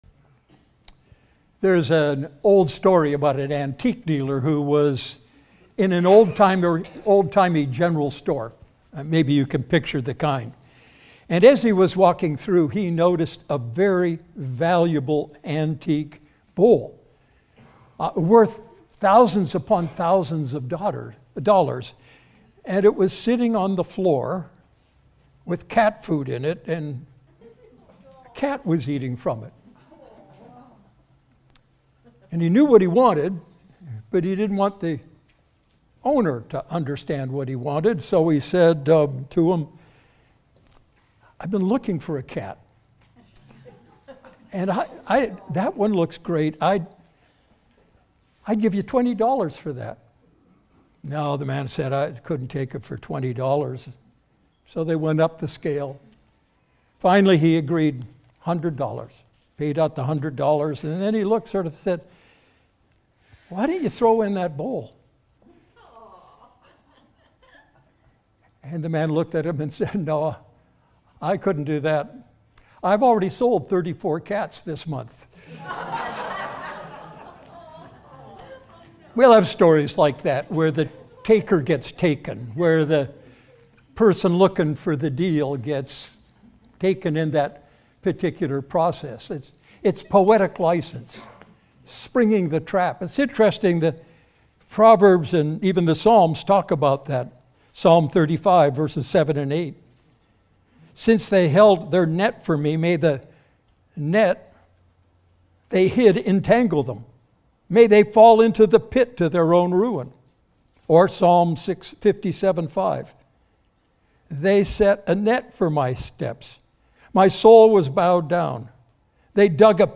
Sermon Archive | Redeemer Fellowship